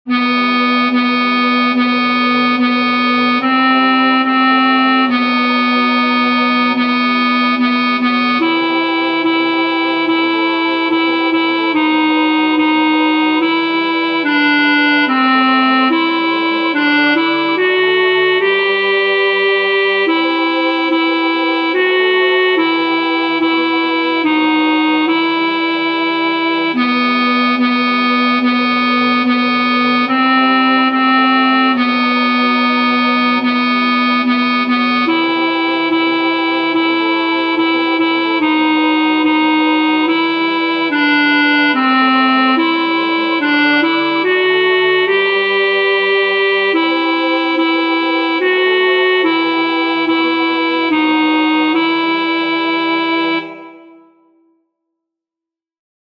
El tempo indicado es Calmo, negra= 72.